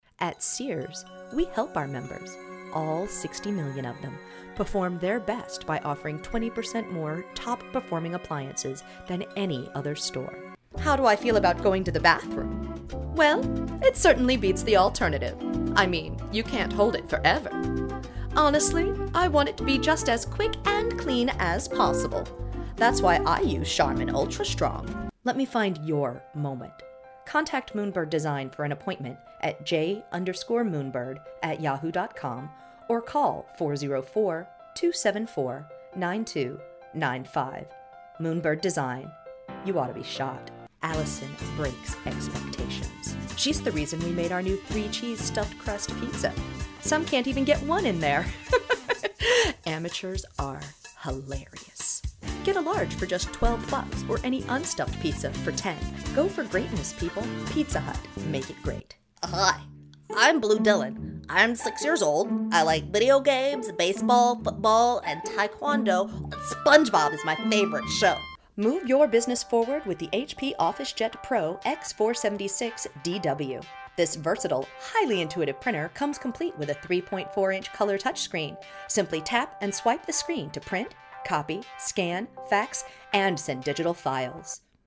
Commercial-VO-Reel.mp3